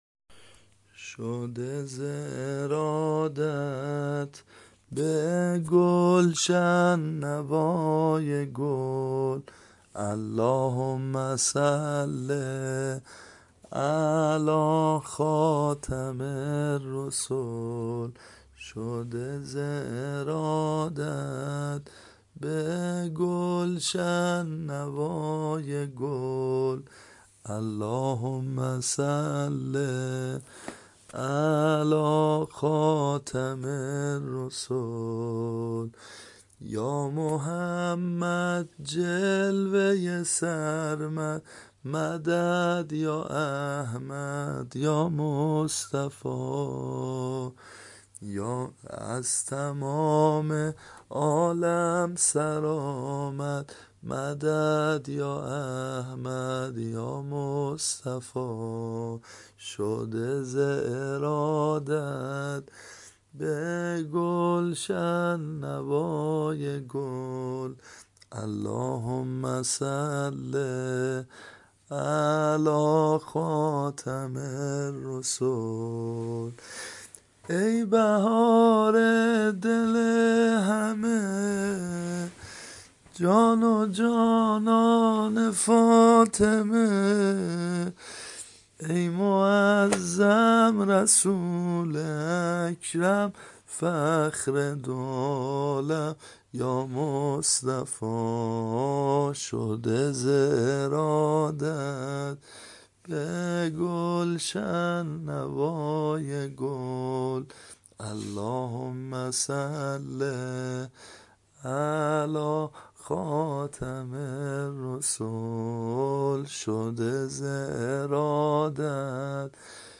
متن شعر و سرود مسجدی ولادت پیغمبر اکرم و امام صادق سلام الله علیهما -(شده زِ ارادت به گلشن نوای گل)